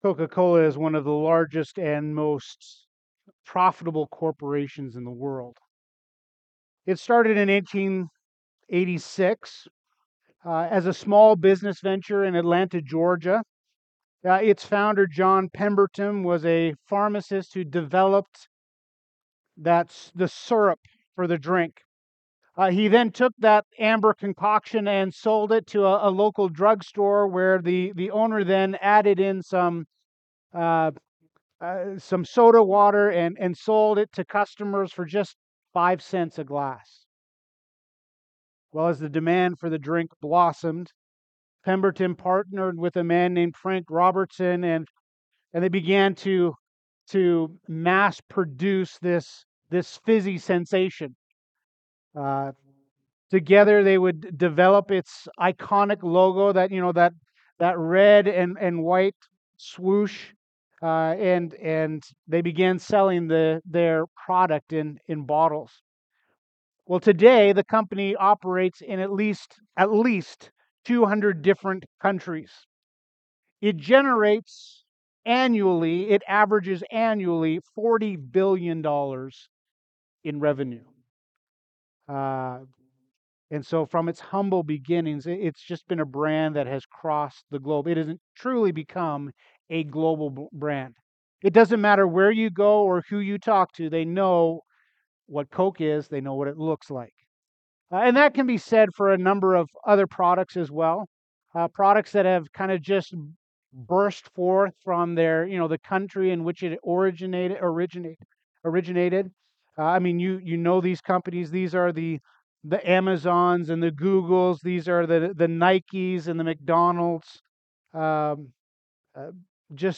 Sermons - Grace Bible Fellowship